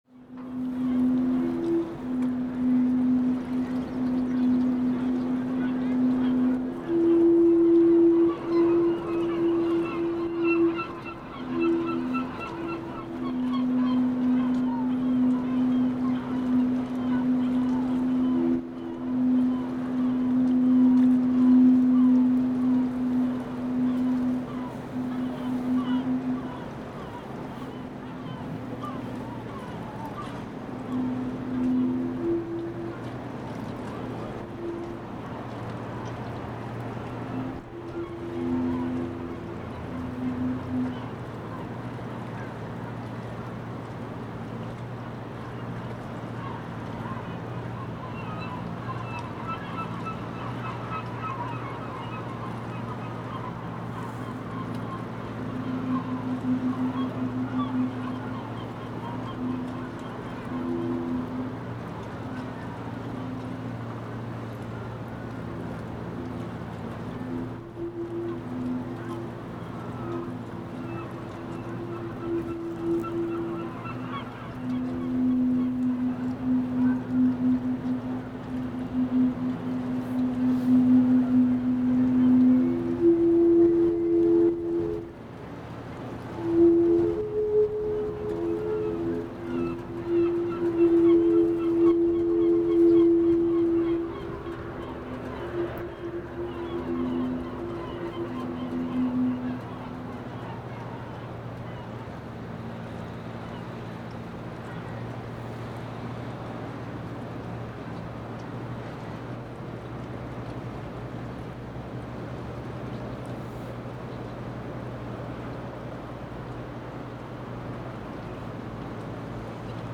Windorgelgeruestton.MP3